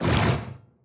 Sfx_creature_bruteshark_swim_fast_03.ogg